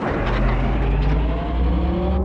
liftstart.wav